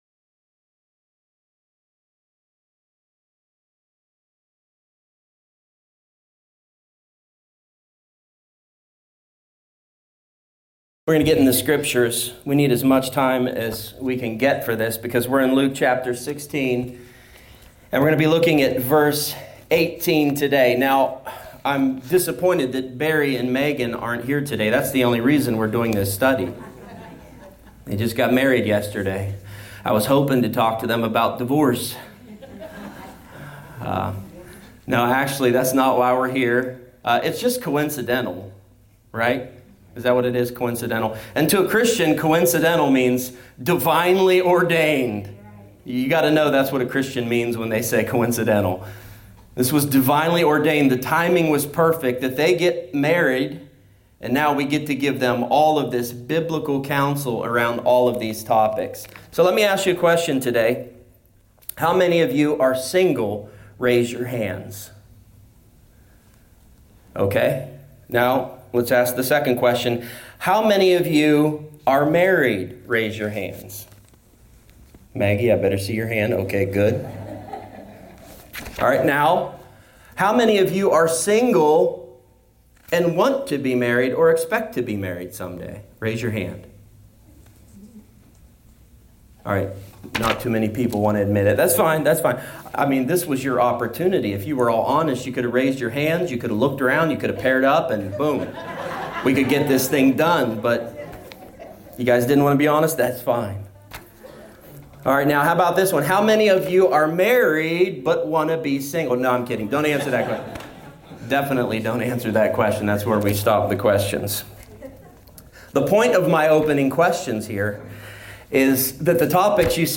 A topical teaching on the subjects of singleness, marriage, divorce, and remarriage.